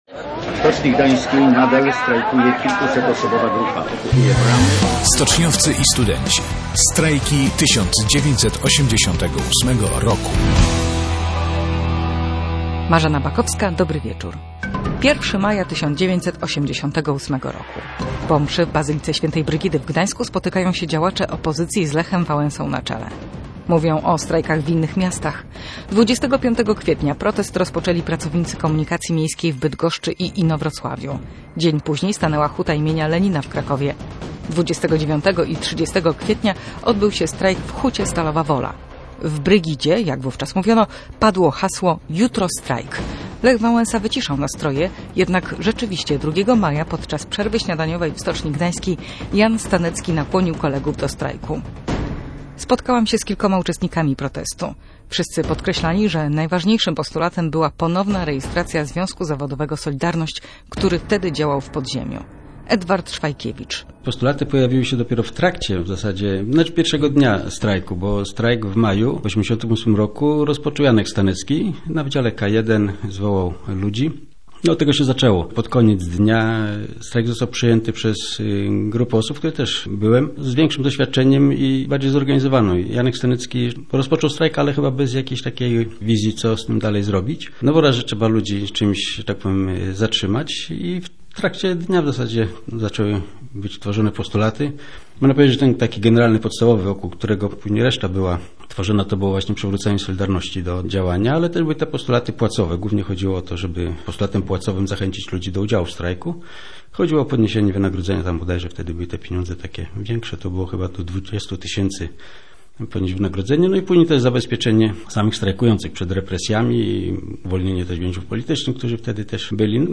O strajku w gdańskiej stoczni opowiedzieli jego uczestnicy